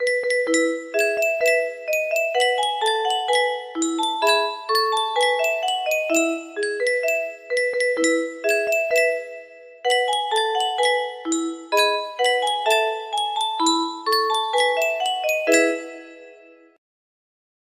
Yunsheng Music Box - The Twelve Days of Christmas 071Y music box melody
Yunsheng Music Box - The Twelve Days of Christmas 071Y
Full range 60